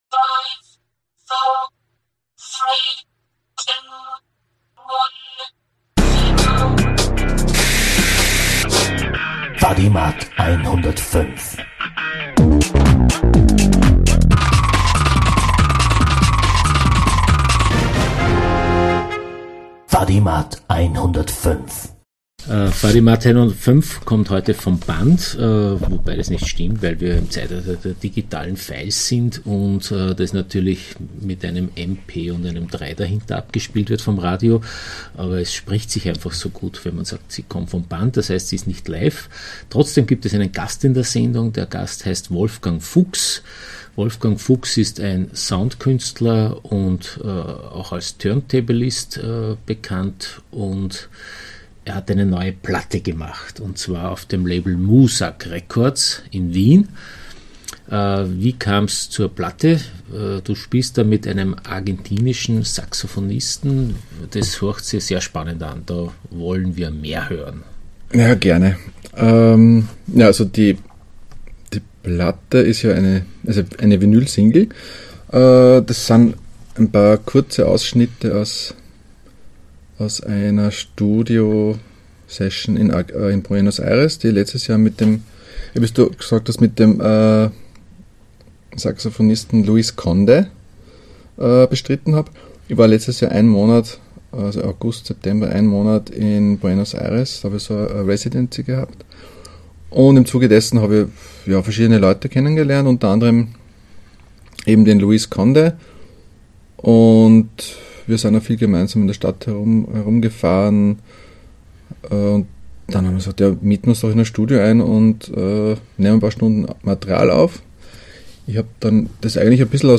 Radio-Feature